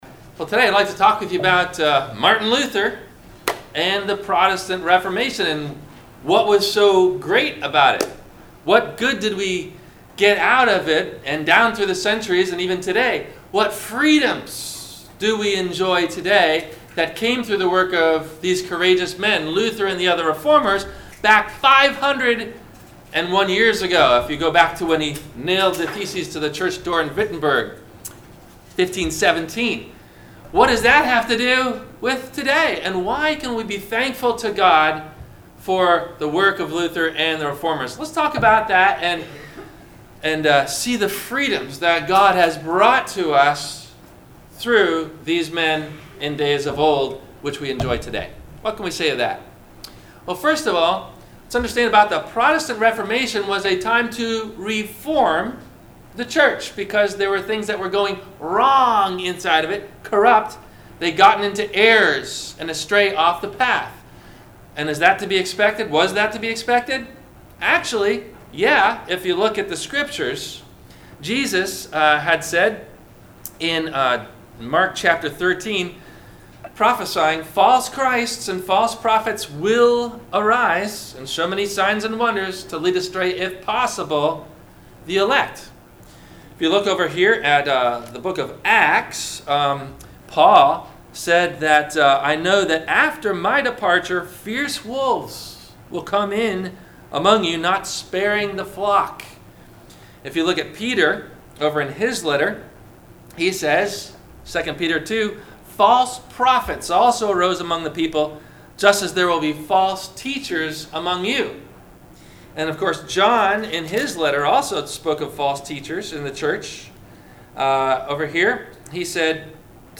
- Sermon - October 28 2018 - Christ Lutheran Cape Canaveral